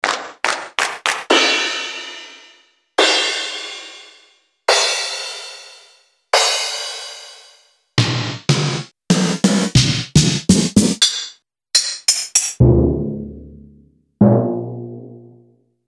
Compact digital drum pad percussion with 6 PCM samples onboard.
demo HEAR pitch tuning
sounds Instrument list: snare (x2), claps, cymbal, timpani and tamboutirine